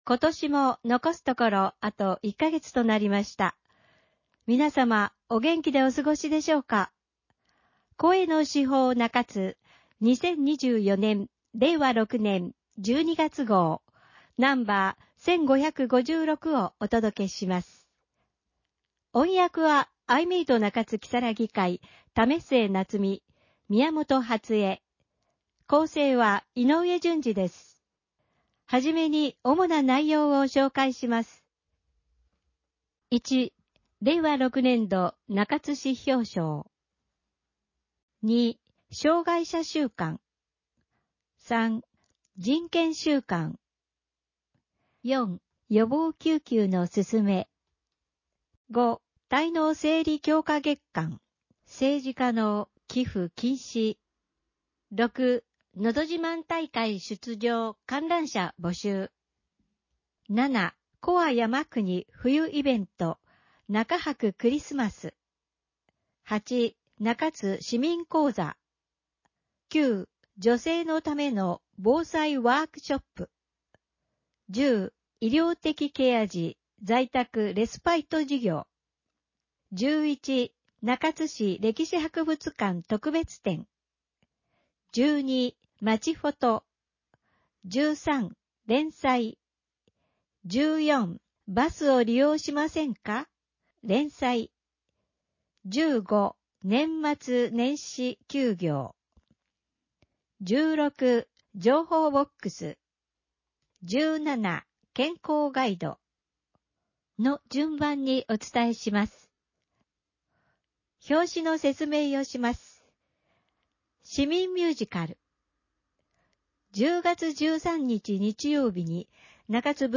市報の内容を音声で聞くことができます。 アイメイト中津きさらぎ会がボランティアで製作しています。